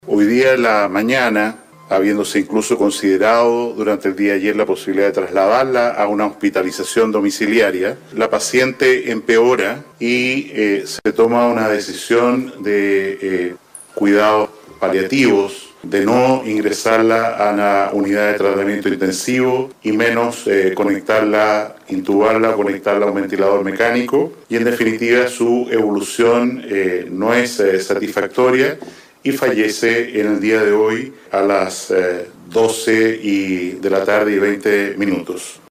De acuerdo a los antecedentes entregados posteriormente por la primera autoridad de salud, en conferencia de prensa, detalló que se trata de una mujer de 82 años, que se encontraba postrada, y que era residente de la comuna de Renca, en la región metropolitana.